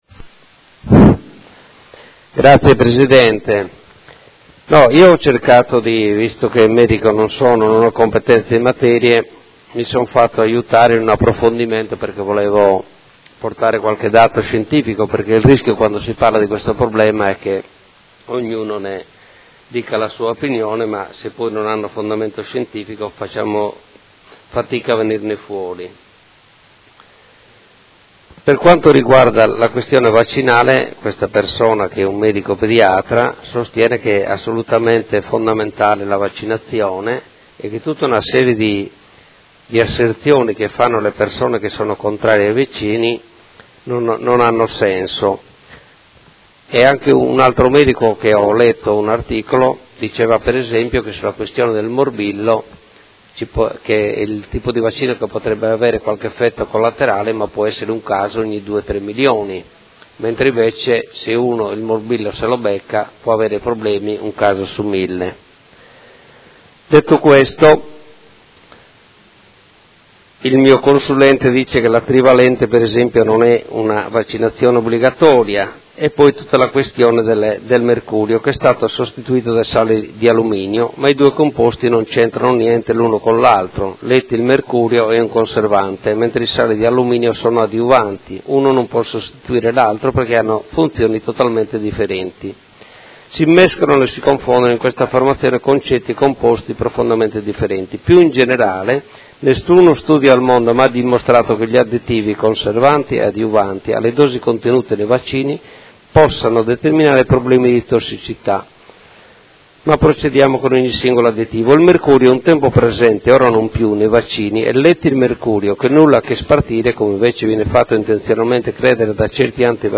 Dibattito su Mozione ed Emendamento riguardanti la copertura vaccinale della popolazione modenese e Ordine del Giorno riguardante le vaccinazioni pediatriche